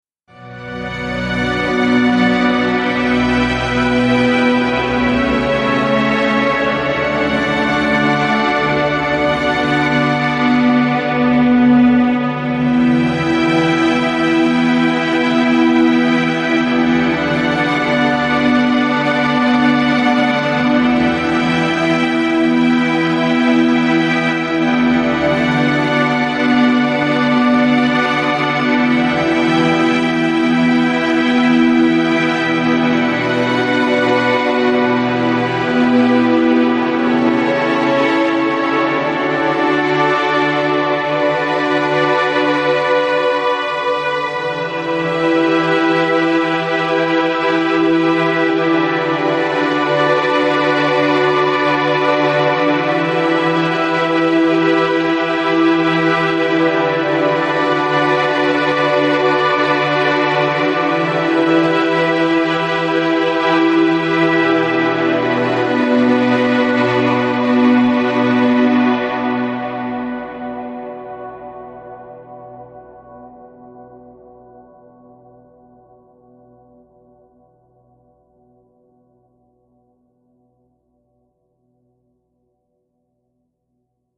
Electronic, Lounge, Chill Out, Ambient, Balearic